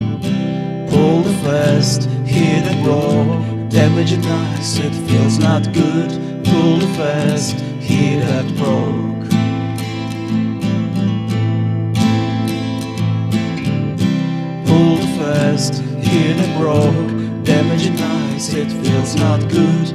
a little more melodic and emotionally charged.
relatively upbeat song